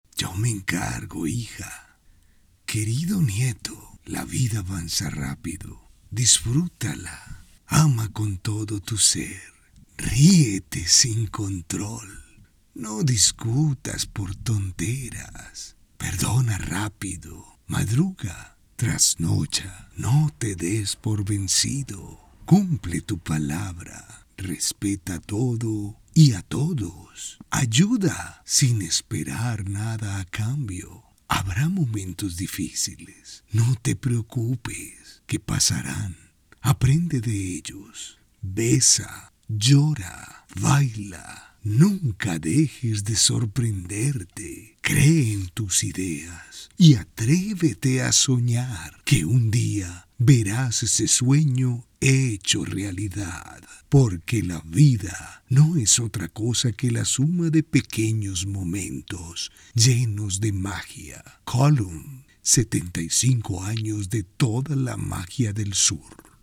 Micrófono Scarlett CM 25 Interfaz Focusrite Scarlett solo 4 Audífonos Scarlet SM 450 Adobe Audition
kolumbianisch
Sprechprobe: Sonstiges (Muttersprache):
My voice range is from 25 to 60 years old. My voice is warm, mature, energetic, happy, brassy, narrator tips.